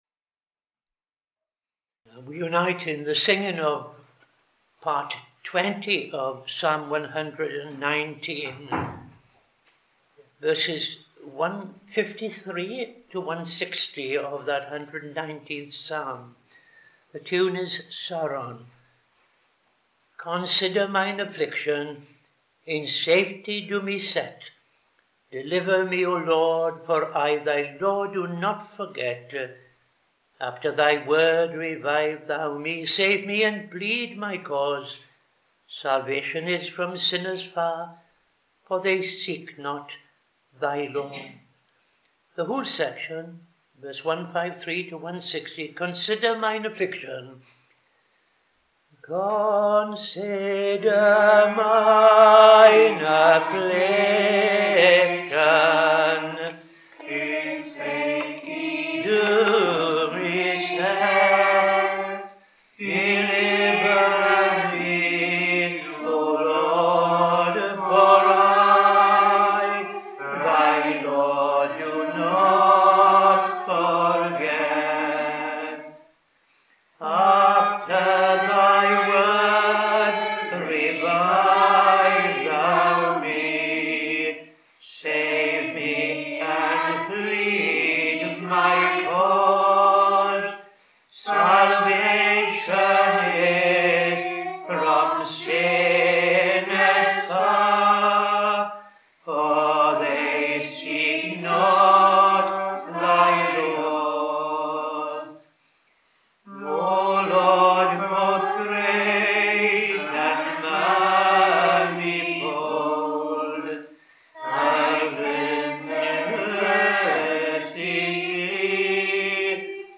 Evening Service - TFCChurch
Psalm 135:15-21 ‘The idols of the nations …’ Tune Glasgow